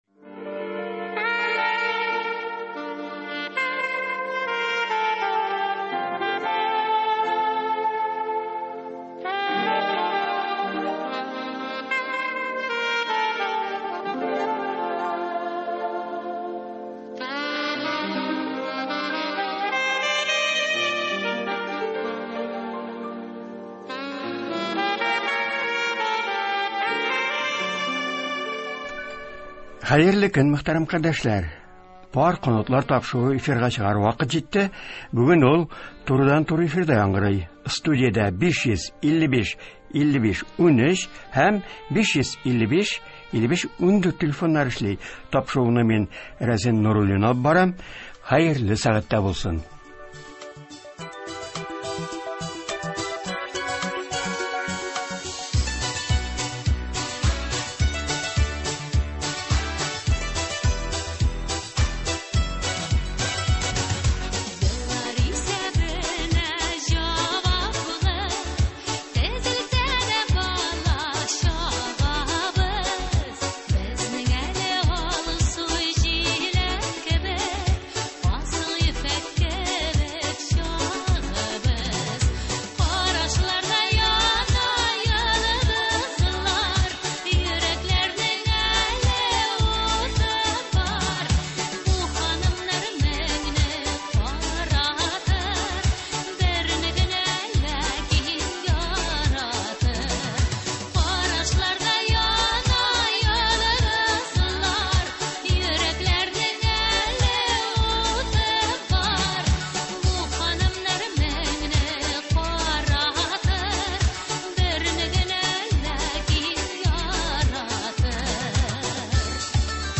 Тапшыру турыдан-туры эфирда барачак.